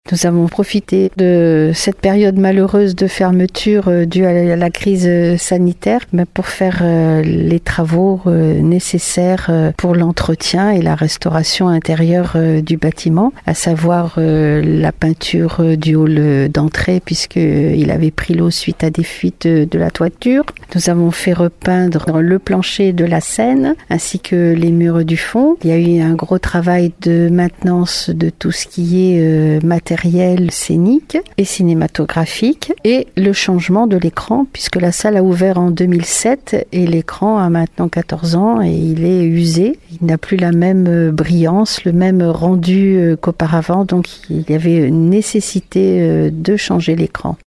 Des travaux ont en effet été réalisés pendant cette période de fermeture forcée, comme nous l’explique Claude Balloteau, maire de Marennes-Hiers-Brouage :